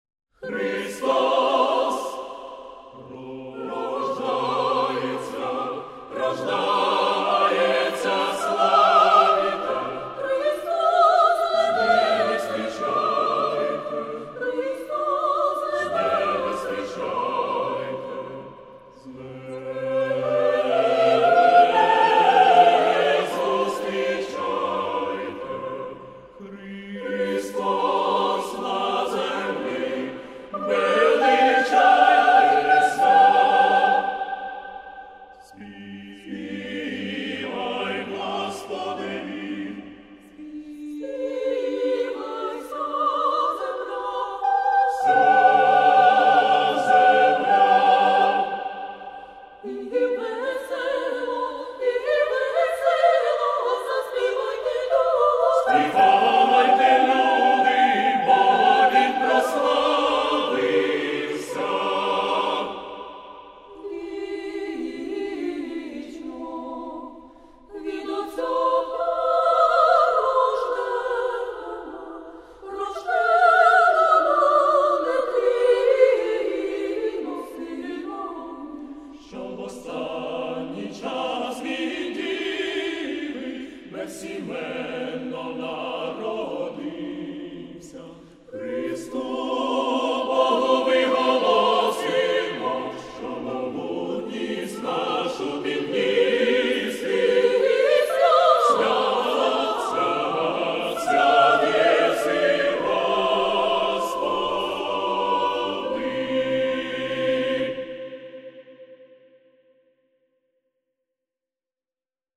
Das Repertoire des rund 20-köpfigen Chores umfasst mehr als 300 Stücke der ukrainischen und  ausländischen geistlichen Musik.
So bunt sich das Repertoire des Chores liest, so vielseitig klangen die Lieder des Konzertes, die allesamt a-cappella, also ohne Begleitung, dargebracht wurden.